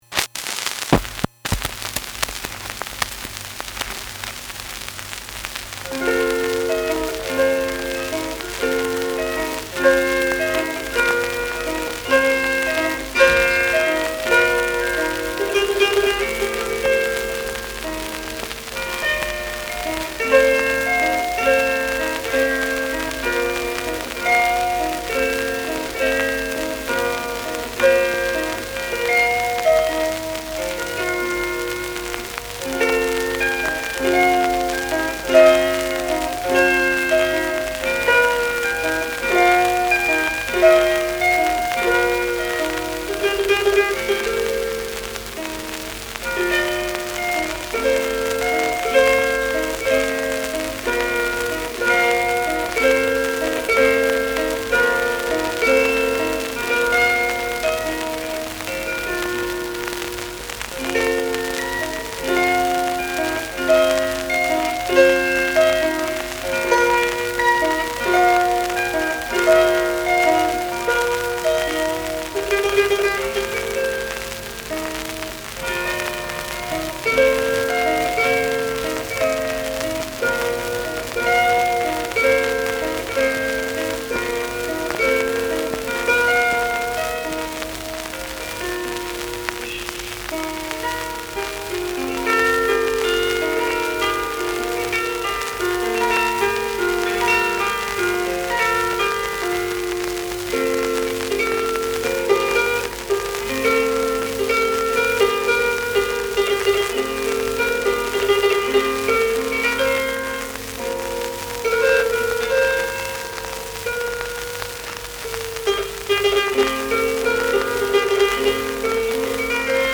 I have transferred them using a hifi turntable and lightweight pickup.
on the Bardic Harp